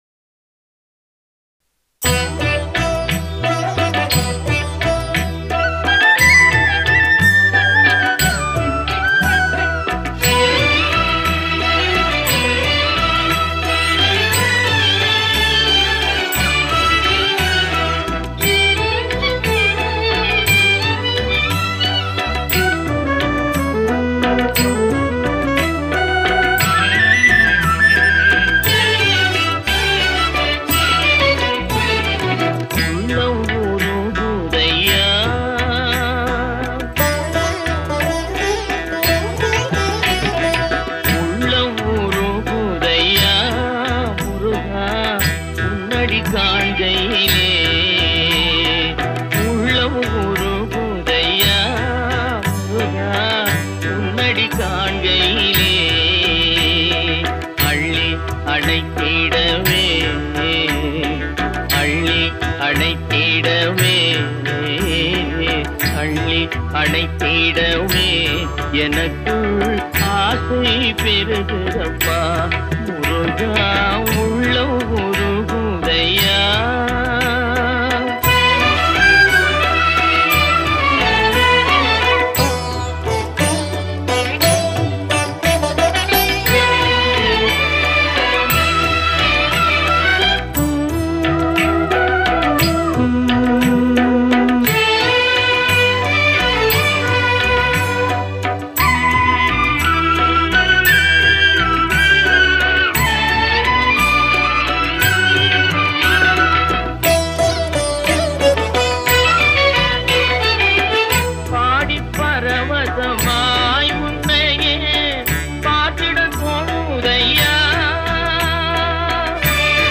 classic devotional song